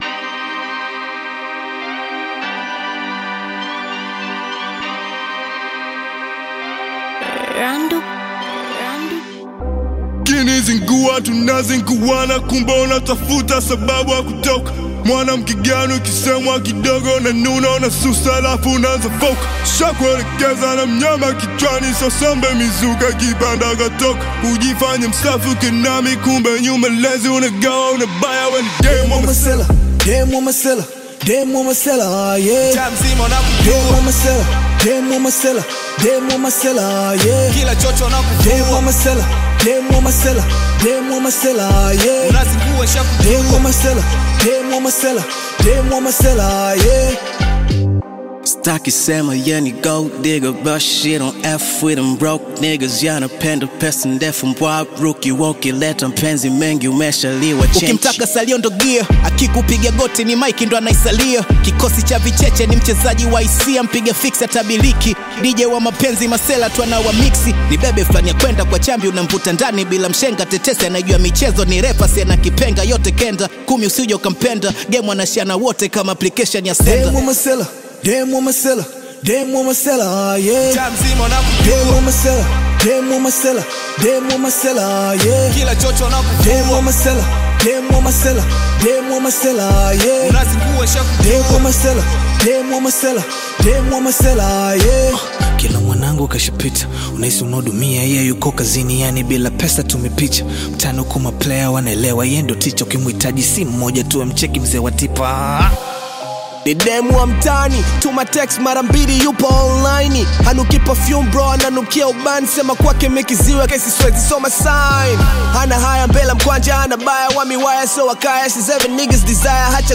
vibrant Tanzanian Bongo Flava/Hip-Hop single
blends playful storytelling with rhythmic Afro-urban beats
lively delivery and modern Tanzanian sound